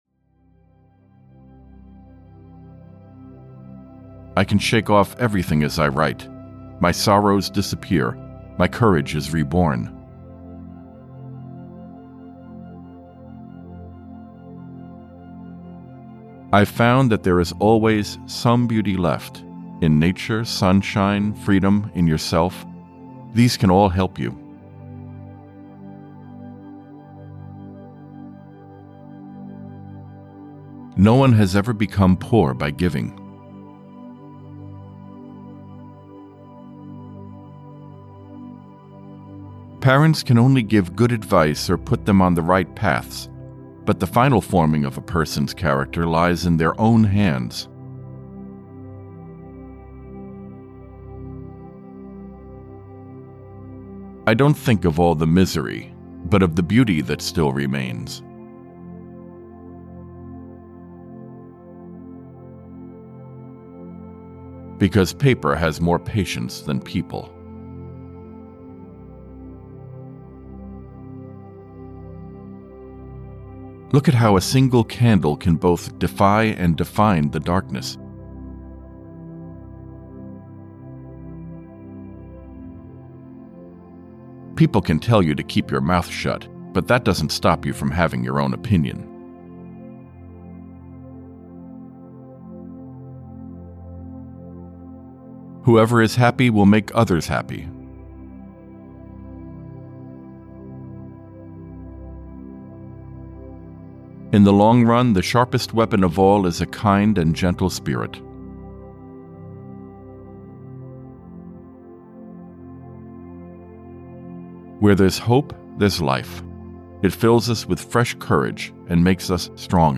Audiobook ‘300 Quotes from Inspiring Women’ is a collection of inspirational, humorous, and insightful quotes from women who have left an indelible mark on history with their bravery, wisdom, and selflessness.
Ukázka z knihy